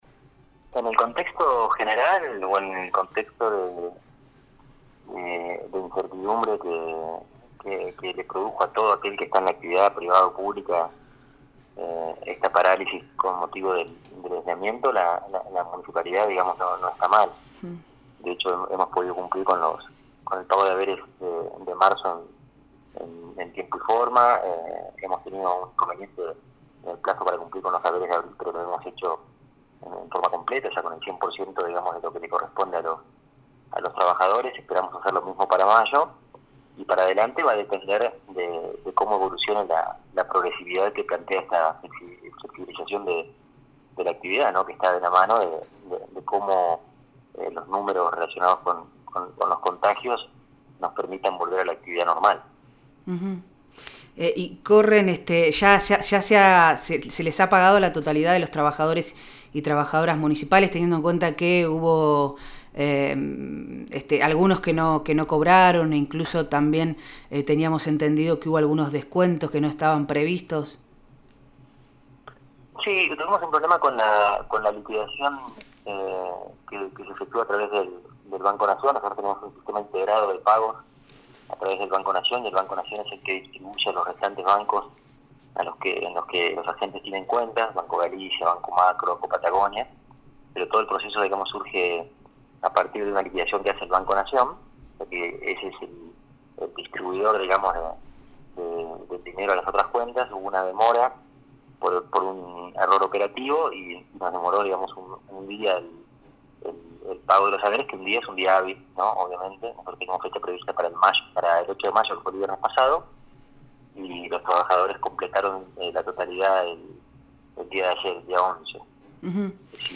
El riesgo para el pago de salarios Diego Quintana , Secretario de Hacienda de la Municipalidad de Bariloche, en diálogo con Proyecto Erre brindó información sobre el estado de las cuentas del municipio. En ese sentido habló sobre el riesgo que existe con el pago de los salarios de los trabajadores y trabajadoras, y mencionó las estrategias para recaudar en el marco de un escenario de crisis social y económica.